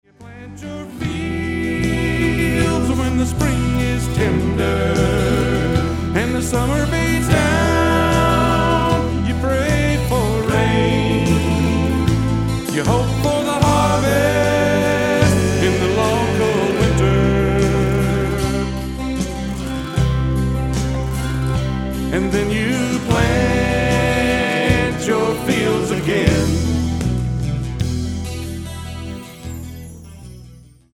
Autoharp, Lead & Harmony Vocals
Mandolin